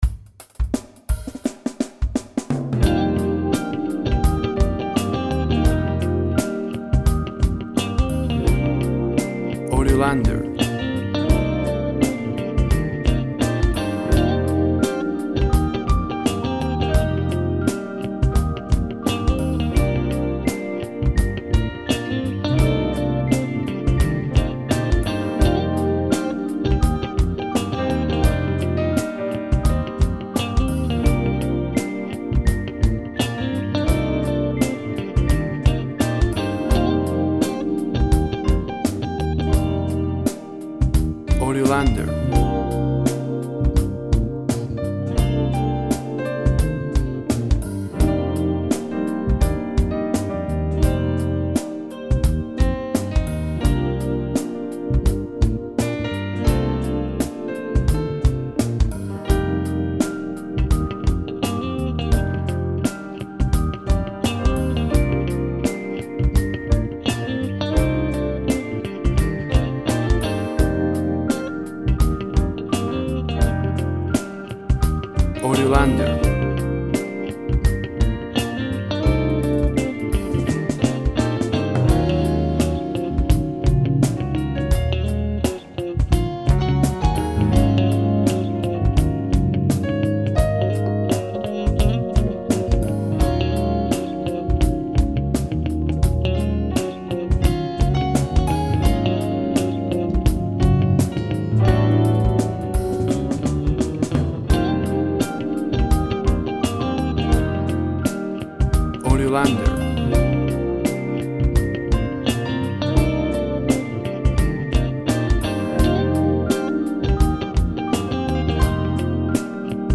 Urban sound with jazz elements.
Tempo (BPM) 85